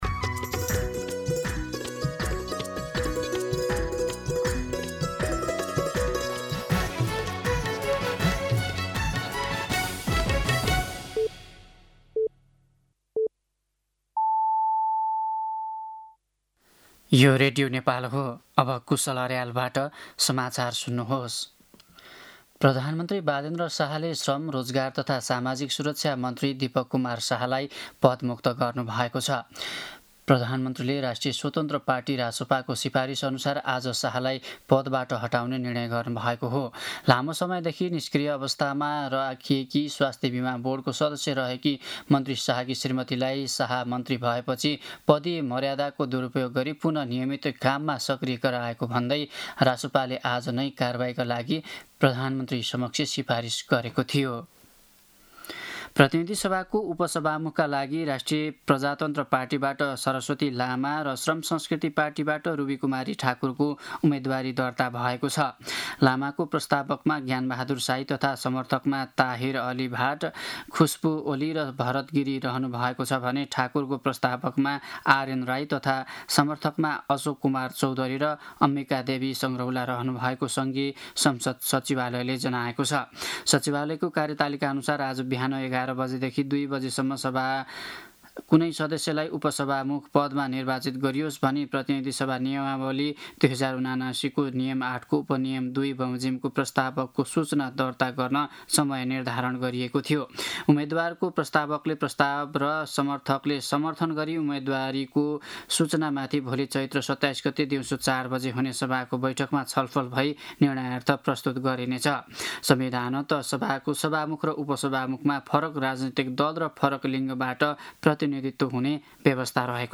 दिउँसो ४ बजेको नेपाली समाचार : २६ चैत , २०८२
4-pm-News-26.mp3